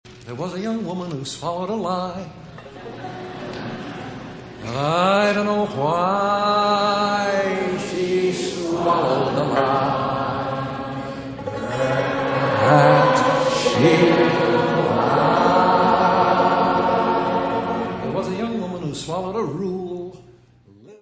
: stereo; 12 cm
Music Category/Genre:  World and Traditional Music